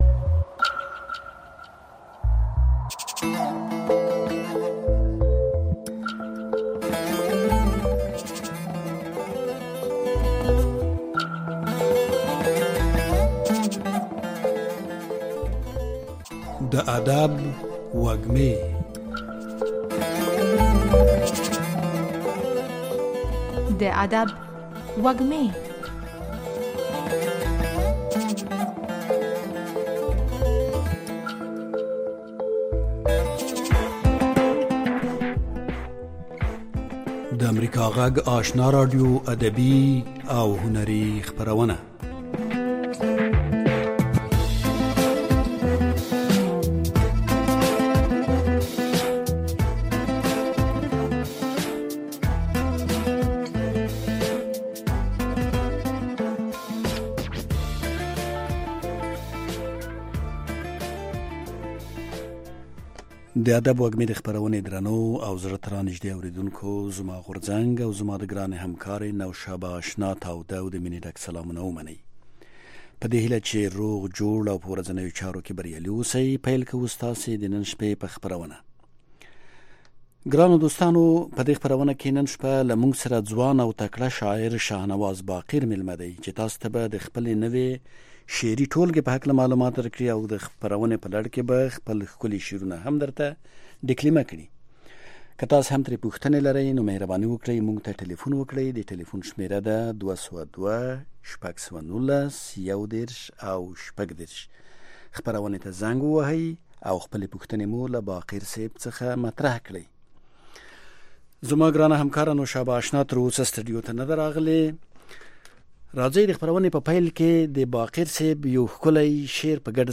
خبرې اترې
په دغې خپرونه کې د روانو چارو پر مهمو مسایلو باندې له اوریدونکو او میلمنو سره خبرې کیږي.